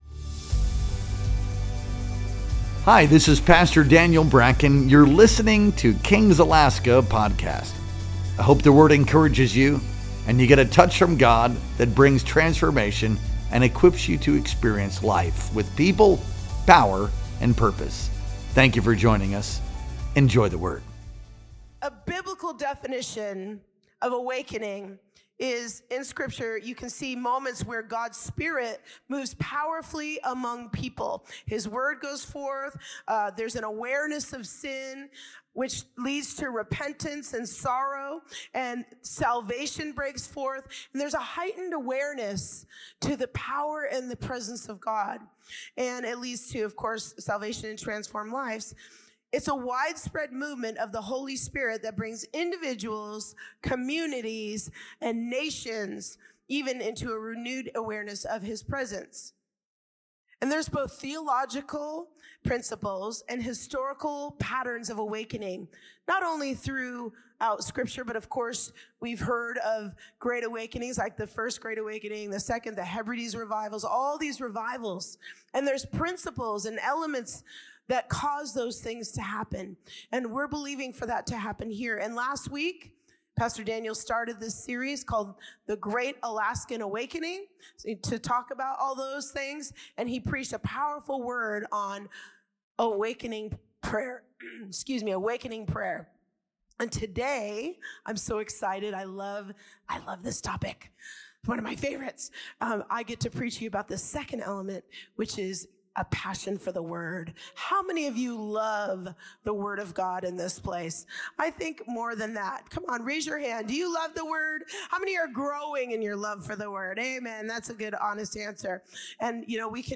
Our Sunday Worship Experience streamed live on March 9th, 2025.